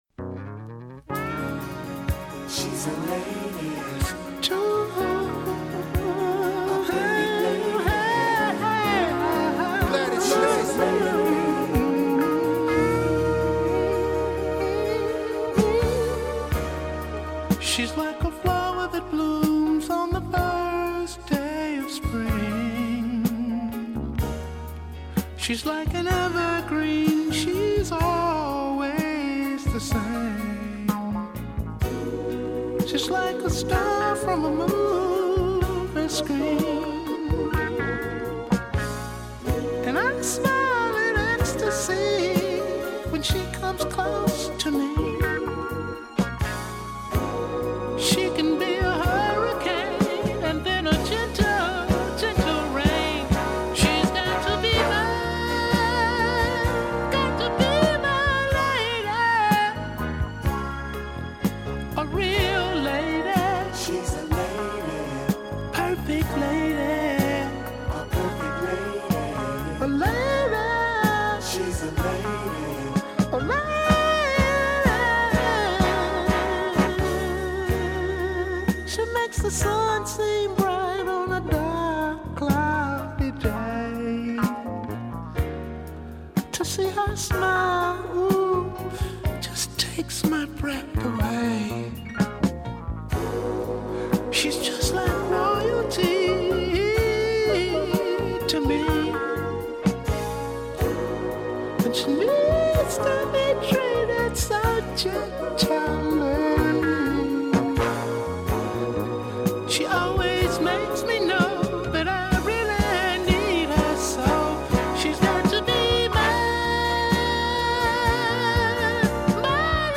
途中入り込むレコードノイズまで意味をもったものとし、愛おしい。
Mellow Groove